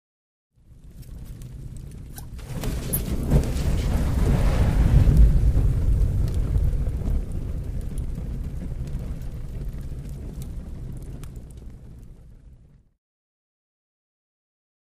LighterFluidPoured HI026801
Lighter Fluid Poured on Fire